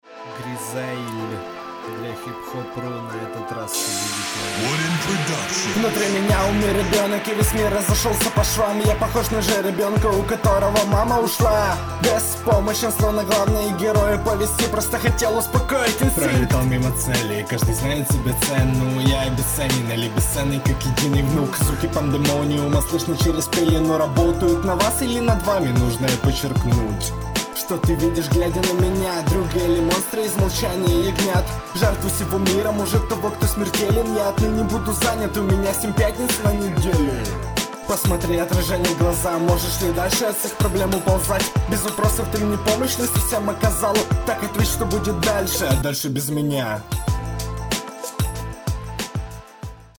По тексту средне, по исполнению тоже.